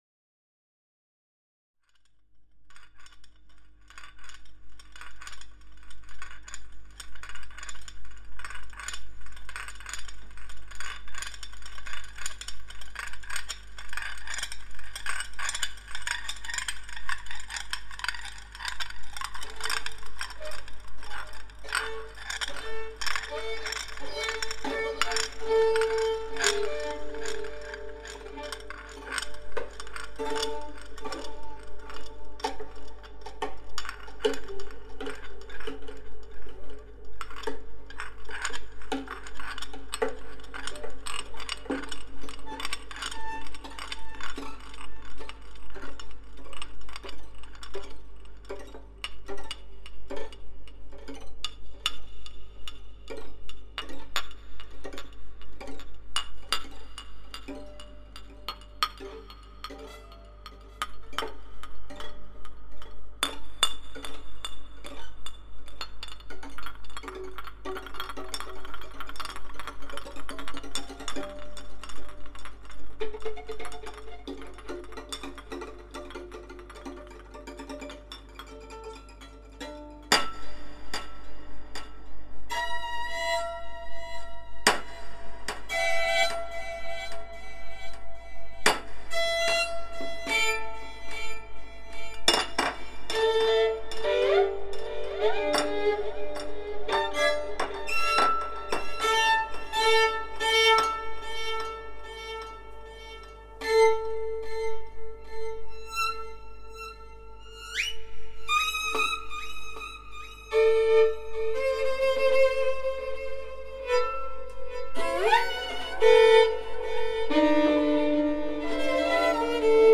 Instrumental Sound Art Improv Avant-Garde
Experimental Ambient Soundtrack